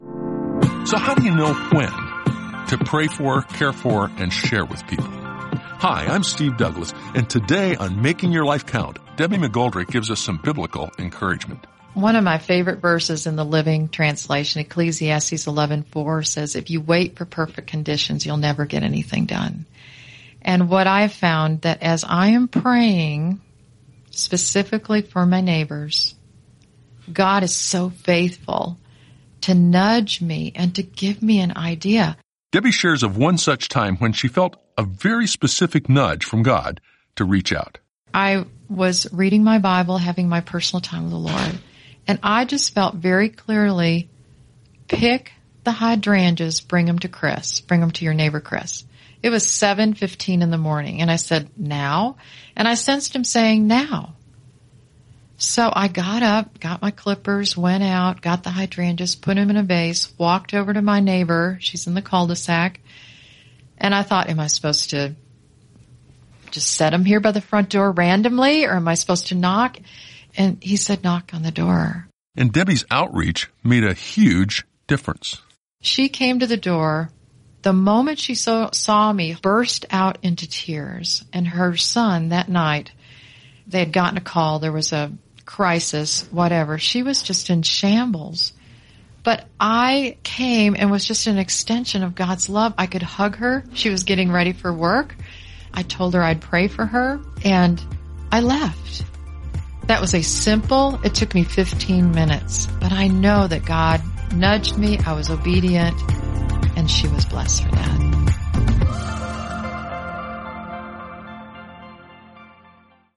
Each of these five devotionals contains a short audio message (1-2 minutes). The guests share about hearing from God, encouraging others, loving unconditionally, and proper boundaries.